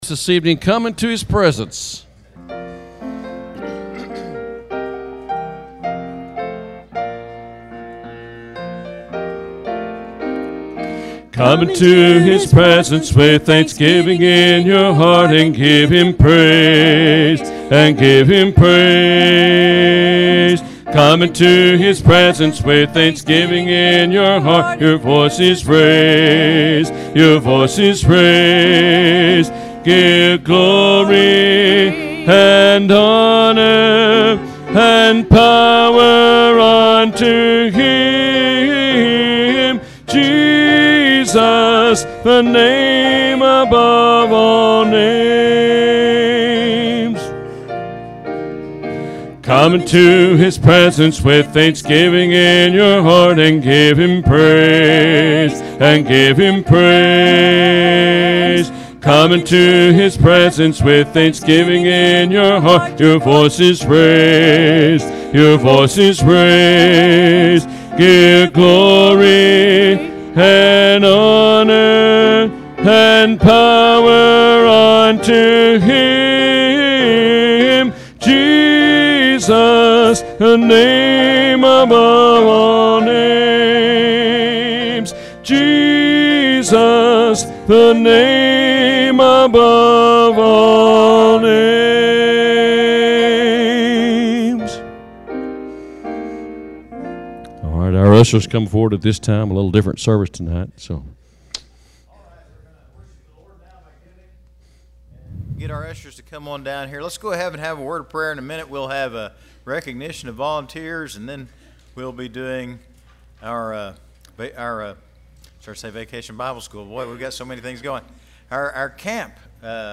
Sermons - South Park Baptist Church
This Celebration Sunday we took the time to honor our volunteers and various ministries. We also had the opportunity to hear some of your youth sing and report back on how Daniel Springs Camp went....